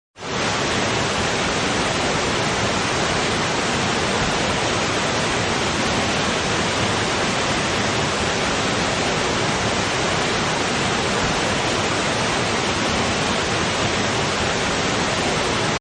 Wasserfall
wasserfall.mp3